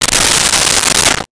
Open Shower Curtain 02
Open Shower Curtain 02.wav